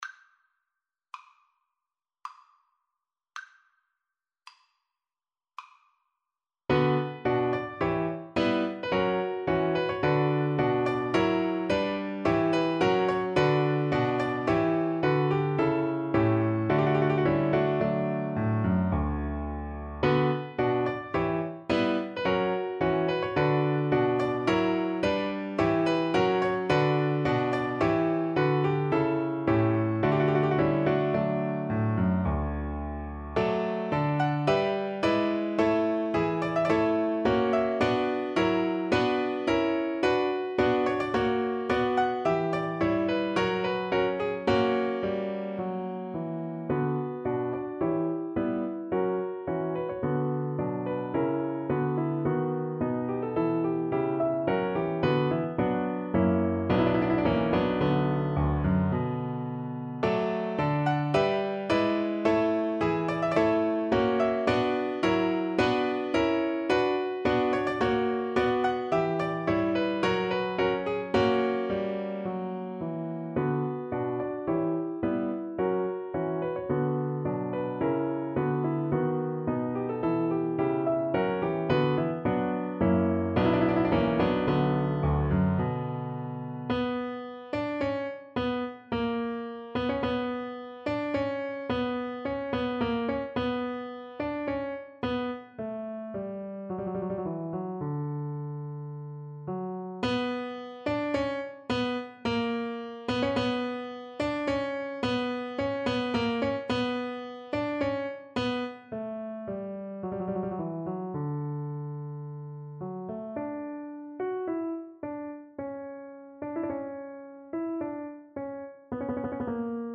Play (or use space bar on your keyboard) Pause Music Playalong - Piano Accompaniment Playalong Band Accompaniment not yet available reset tempo print settings full screen
B minor (Sounding Pitch) (View more B minor Music for Flute )
~ = 54 Moderato